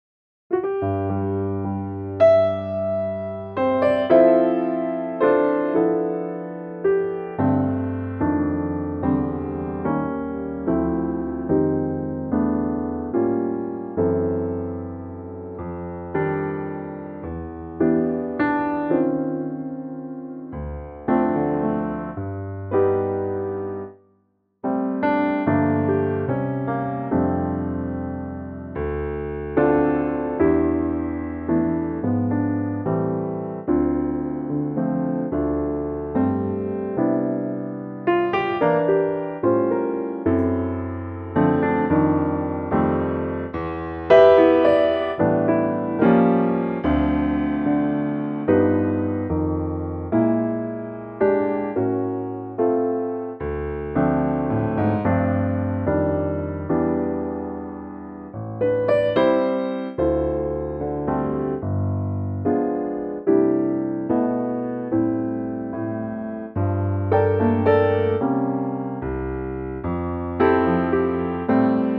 Unique Backing Tracks
key C
key - C - vocal range - D to E
Here's a lovely piano arrangement.